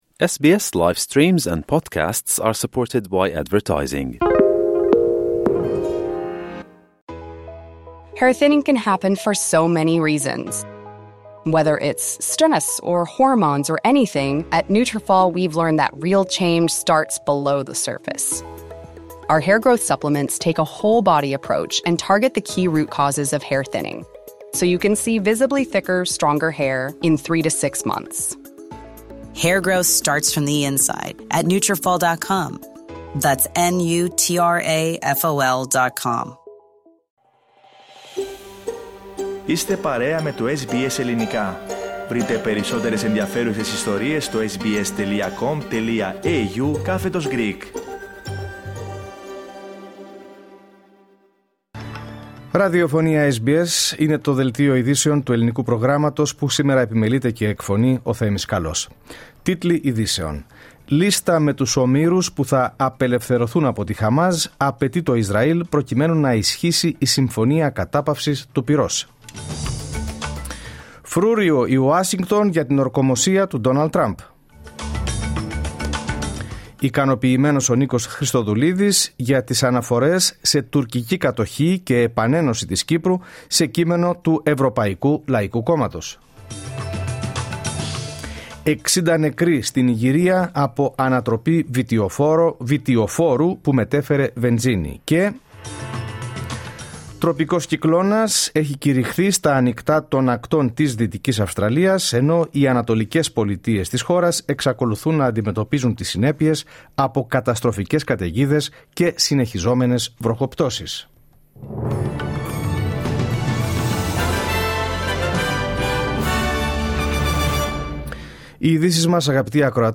Τίτλοι Ειδήσεων